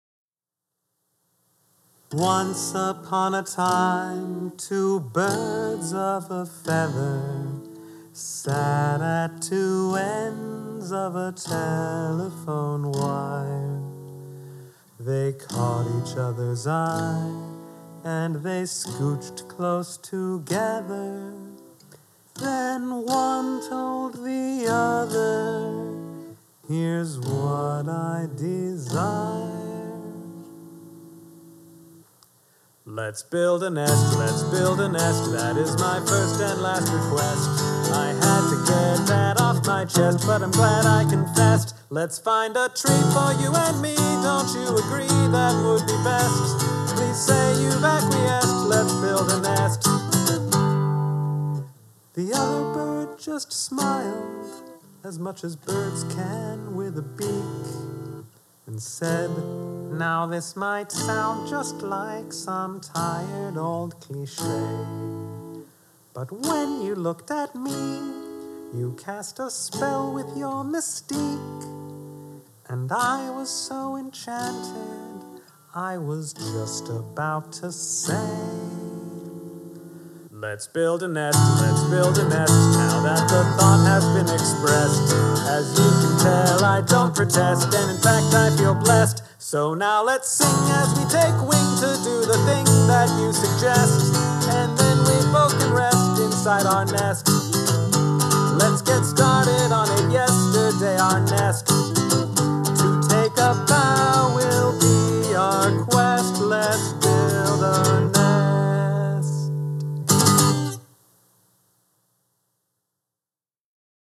There’s no recording of the performance — which is just as well, honestly — so I recorded a home demo version.